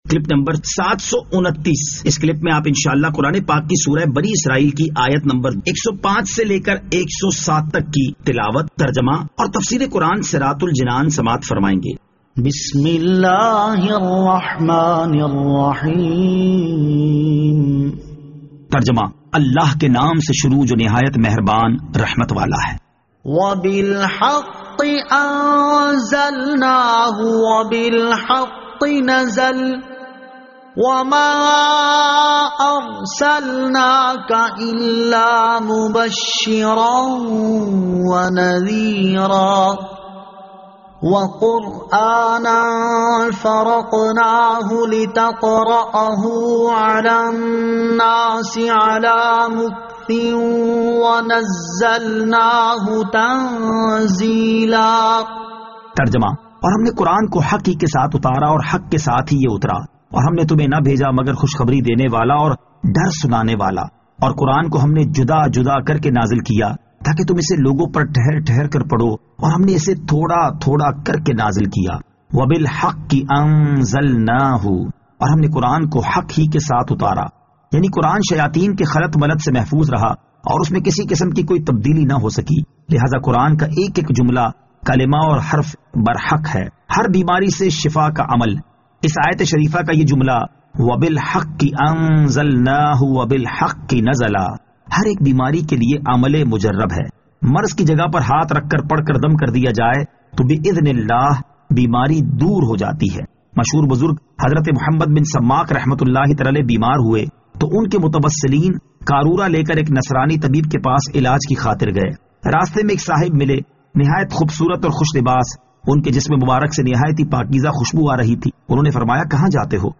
Surah Al-Isra Ayat 105 To 107 Tilawat , Tarjama , Tafseer